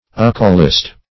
Uckewallist \Uck`e*wal"list\, prop. n. (Eccl. Hist.)